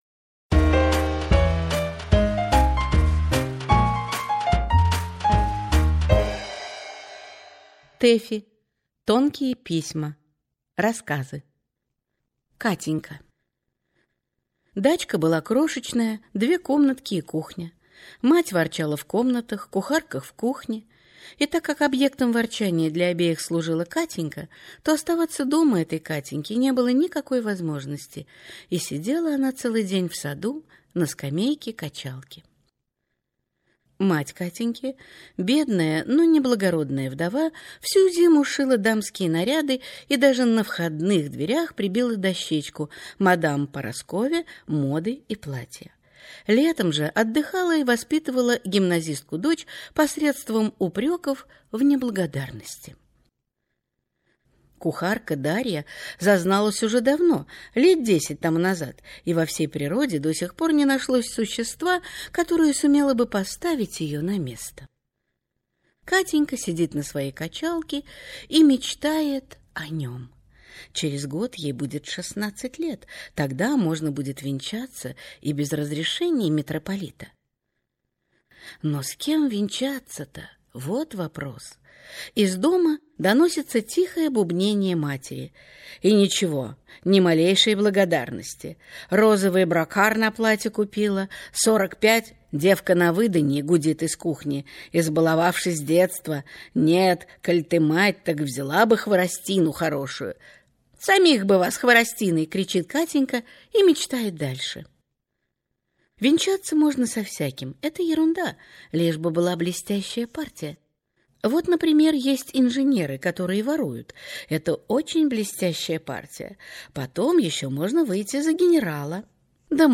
Аудиокнига Юмористические рассказы | Библиотека аудиокниг